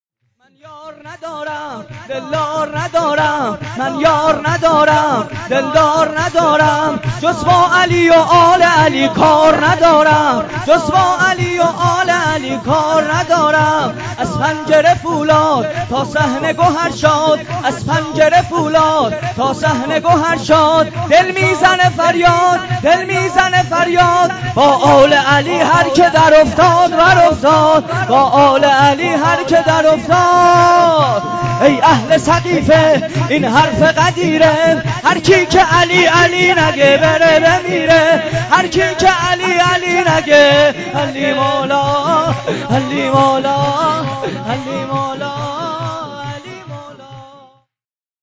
ویژه برنامه جشن بزرگ اعیاد شعبانیه و میلاد انوار کربلا1403